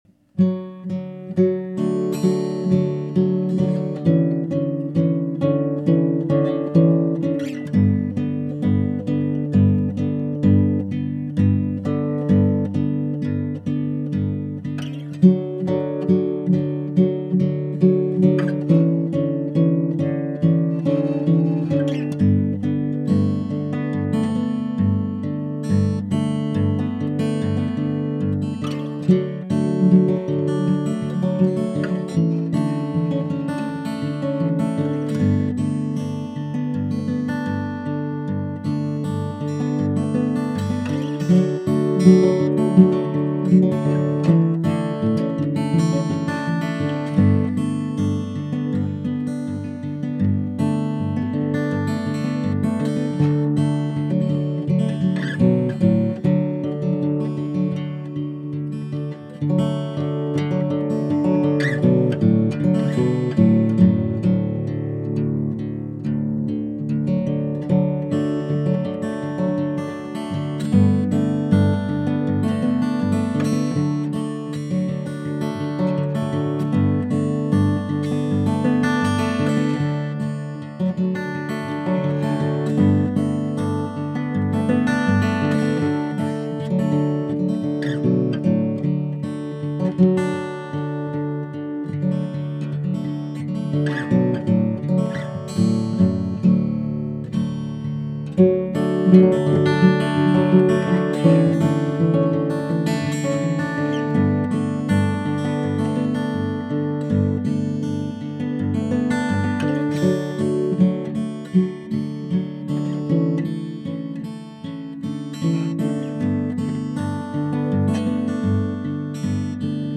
Mighty Good Building, guitar